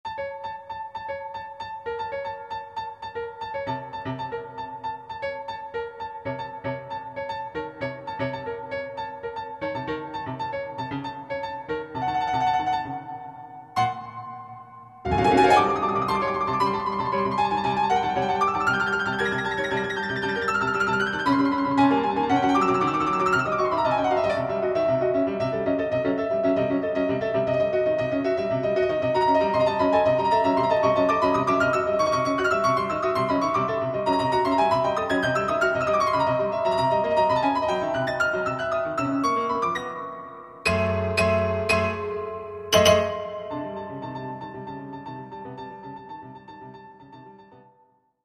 2 klaviere und schlagwerk
so schnell wie möglich